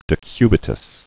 (dĭ-kybĭ-təs)